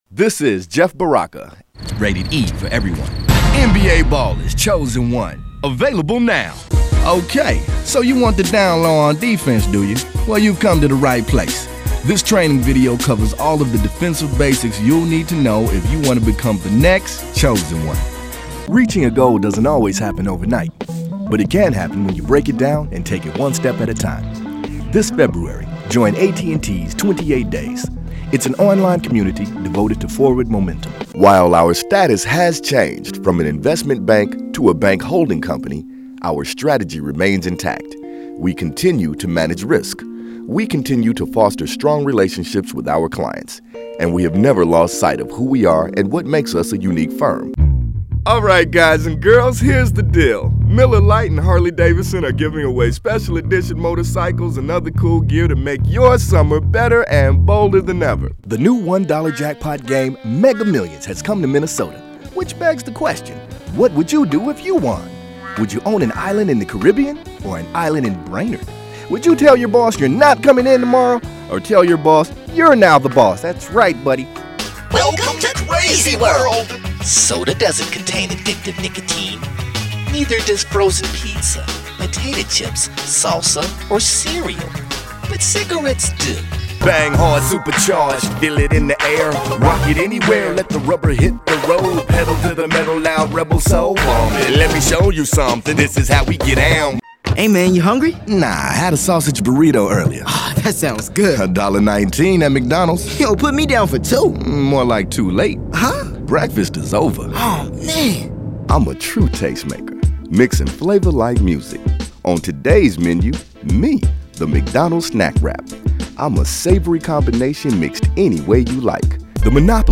The first voiceover demo (above) features a few of my best, most rewarding spots for clients like AT&T, McDonald’s, Miller Brewing, etc. It also includes some of my Midway Games (pre-NetherRealm) video game work for NBA Ballers: Chosen One (hear more below).